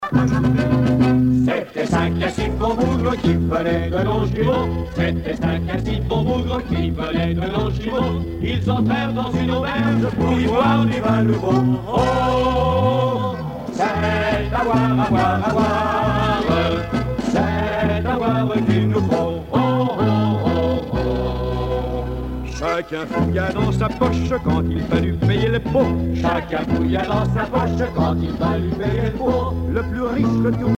danse : marche
circonstance : bachique
Genre strophique
Pièce musicale éditée